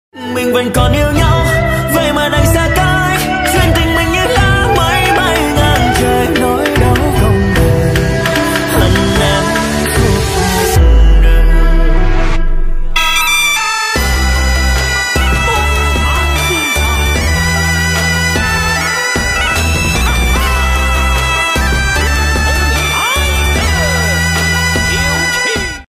chào chào khán giả sau khi diễn